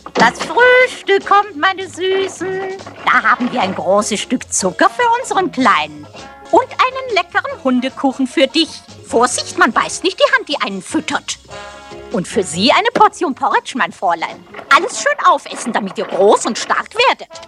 - Krankenschwester